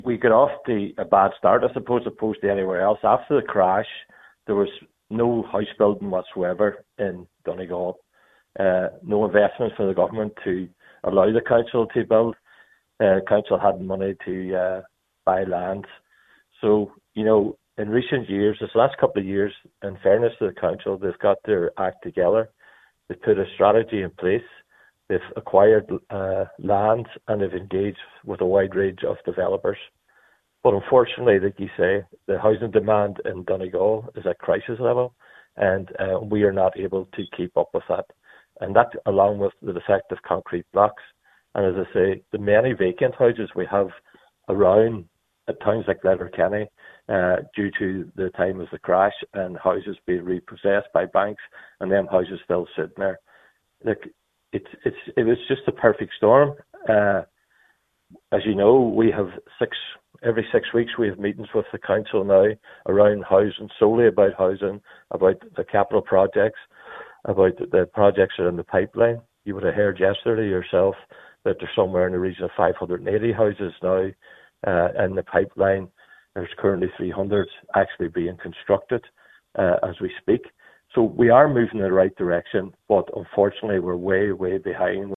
Letterkenny Cllr Gerry McMonagle says it’s the result of years of inaction: